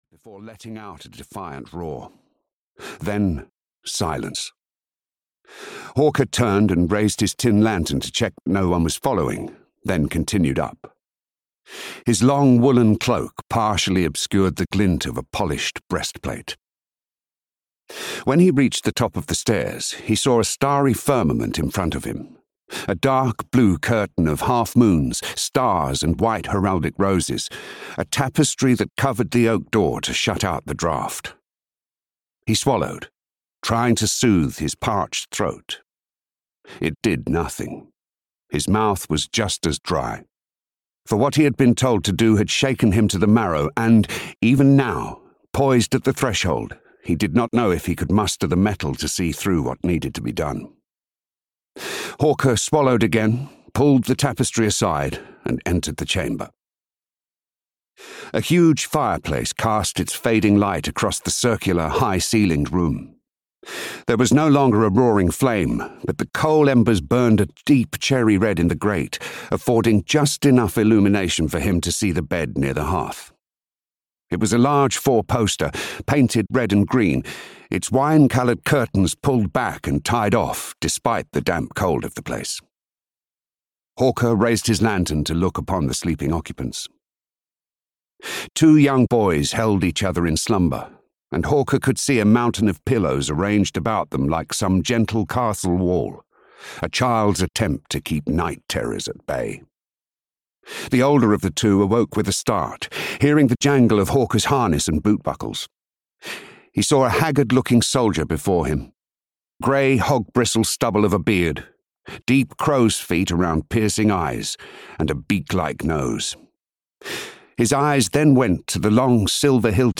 Hawker and the King's Jewel (EN) audiokniha
Ukázka z knihy